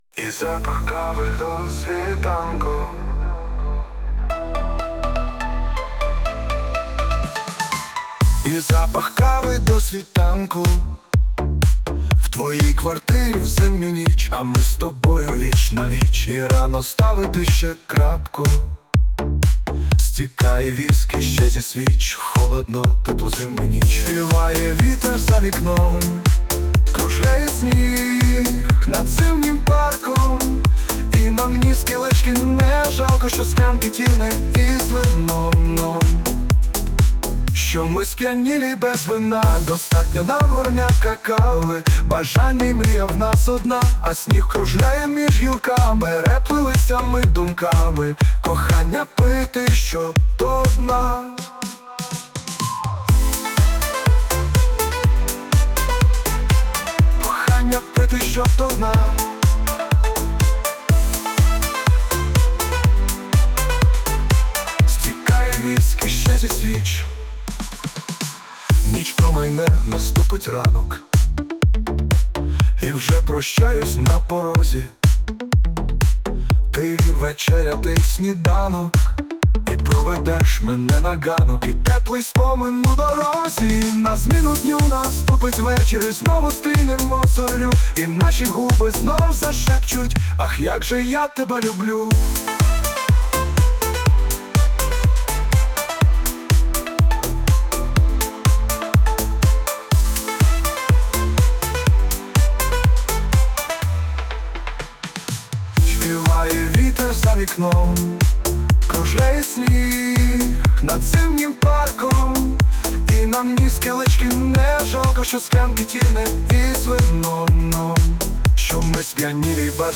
ТИП: Пісня
СТИЛЬОВІ ЖАНРИ: Ліричний
Чудова лірична пісня! hi 12